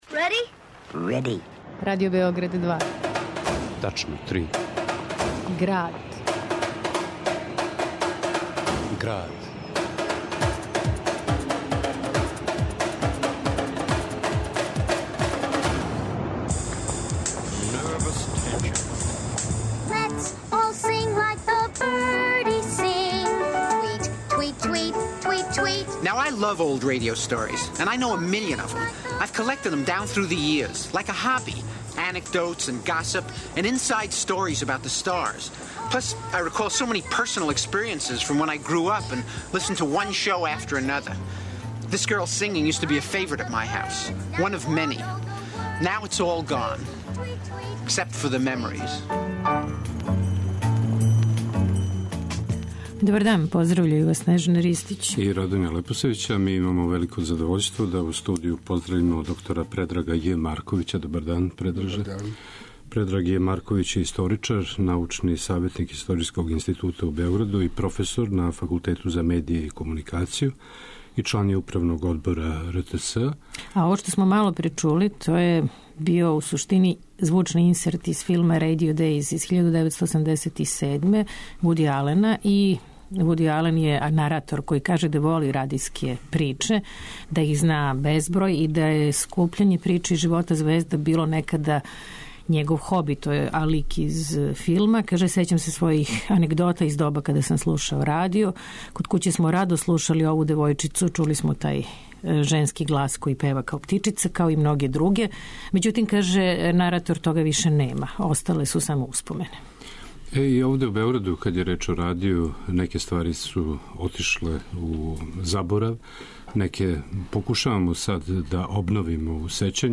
уз архивске снимке